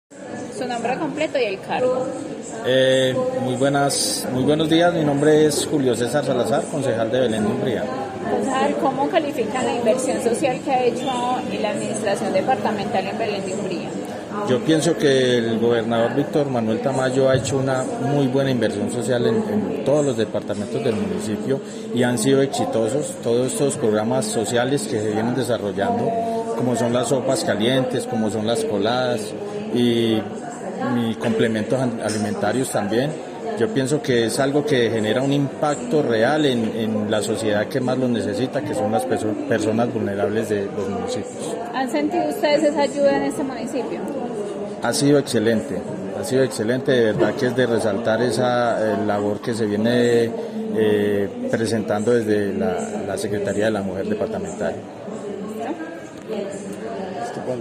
Julio César Salazar, Concejal de Belén de Umbría.